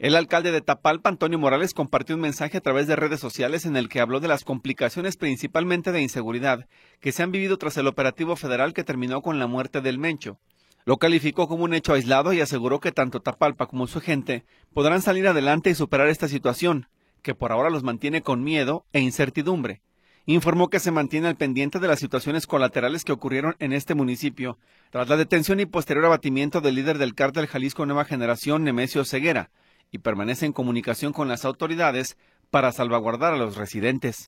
Alcalde de Tapalpa llama a la calma tras operativo federal
El alcalde de Tapalpa, Antonio Morales, compartió un mensaje a través de redes sociales en el que habló de las complicaciones principalmente de inseguridad que se han vivido tras el operativo federal que terminó con la muerte de ‘El Mencho’. Lo calificó como un hecho aislado y aseguró que tanto Tapalpa como su gente podrán salir adelante y superar esta situación que por ahora los mantiene con miedo e incertidumbre.